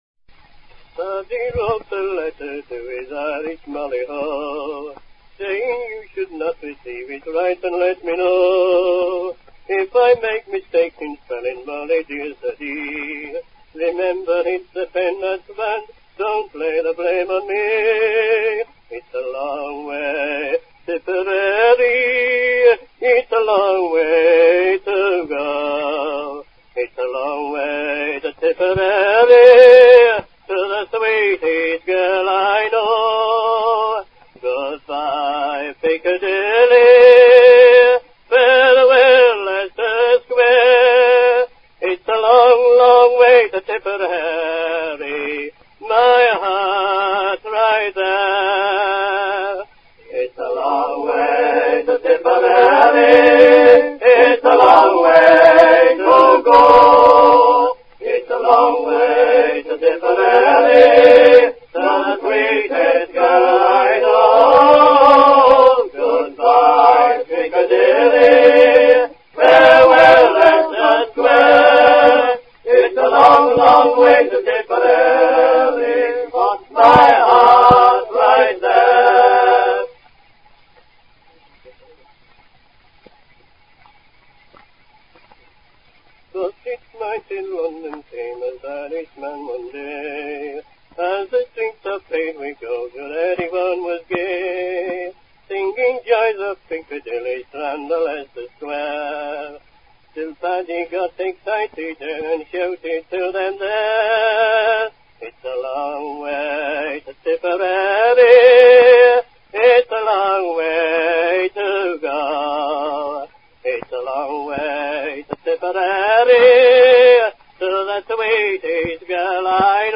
Песня «It's a long way to Tipperary…», как утверждают (и я склонен этому верить), звучит там в исполнении английских военнопленных.